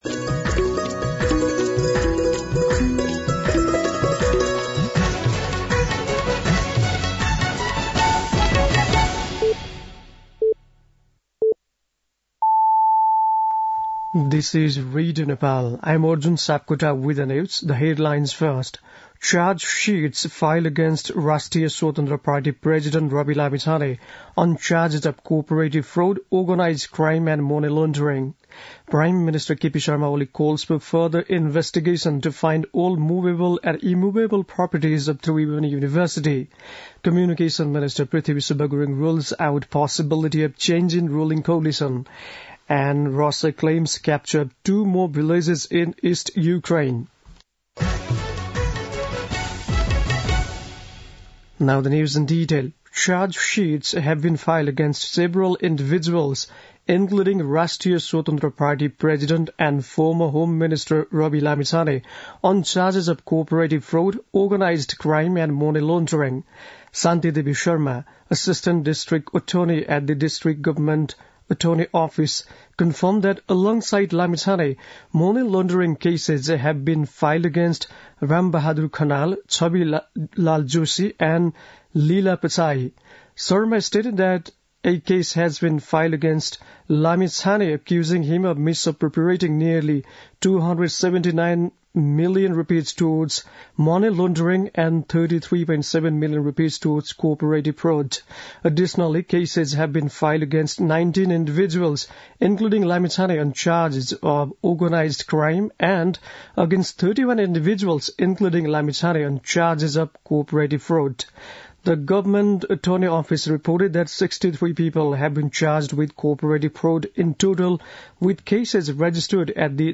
बेलुकी ८ बजेको अङ्ग्रेजी समाचार : ८ पुष , २०८१
8-PM-English-News-9-7.mp3